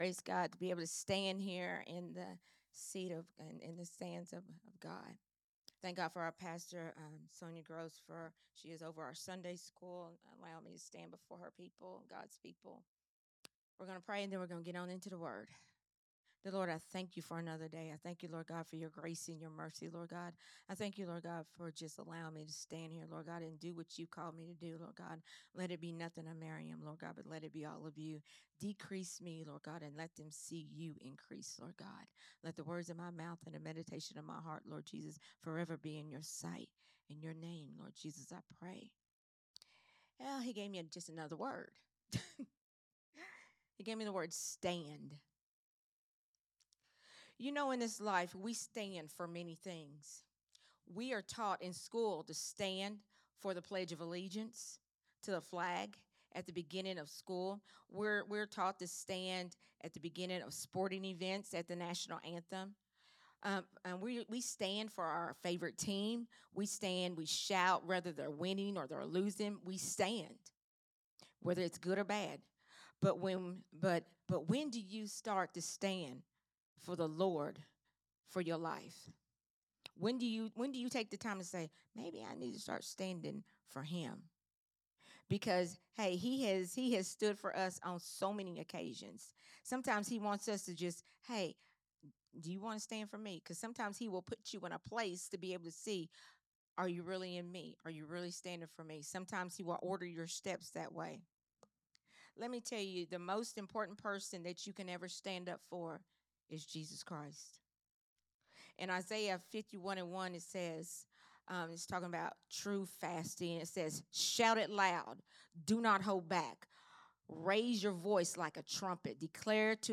a Sunday Morning Risen Life teaching
recorded at Growth Temple Ministries on Sunday